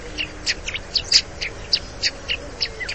Passera mattugia
Passer montanus
Ricorda quella della Passera oltremontana, ma è più acuta, tersa e melodiosa. Caratteristico è il richiamo in volo: un acuto ‘tek tek’.
Passera_Mattugia.mp3